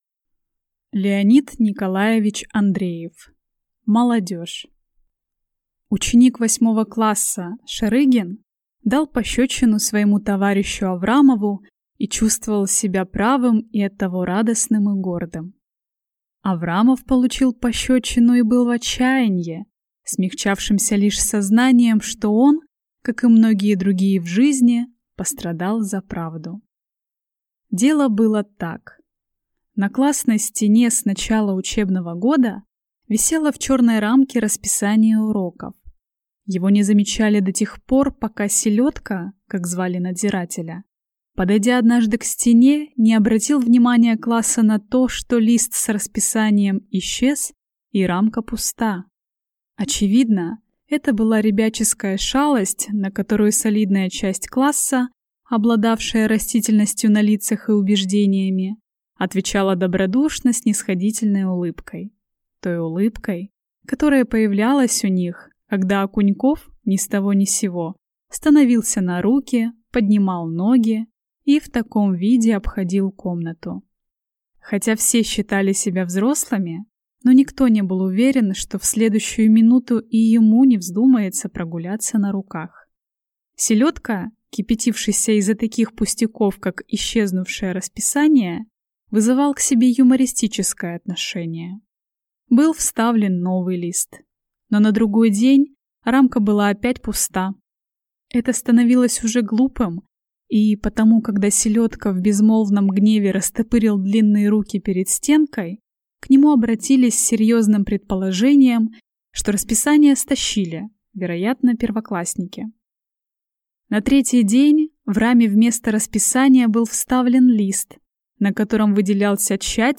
Aудиокнига Молодежь